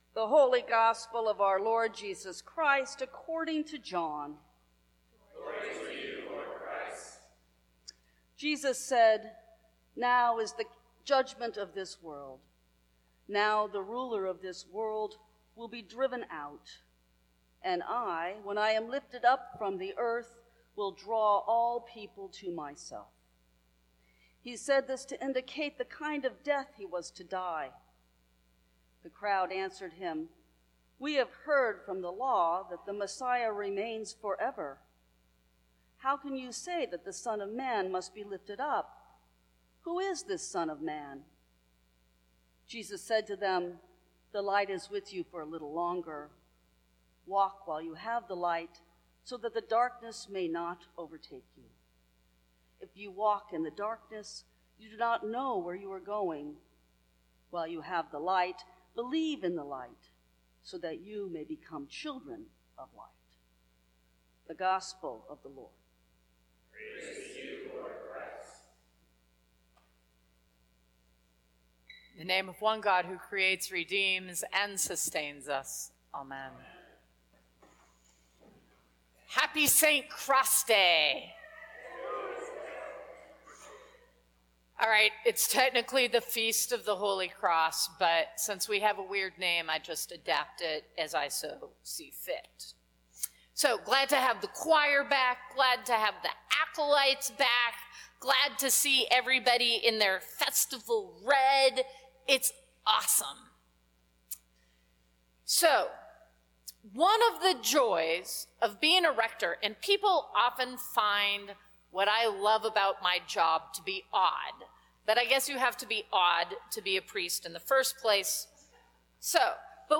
Sermons from St. Cross Episcopal Church St. Cross Day Sep 11 2017 | 00:26:59 1x 00:00 / 00:26:59 Subscribe Share Apple Podcasts Spotify Overcast RSS Feed Share Link Embed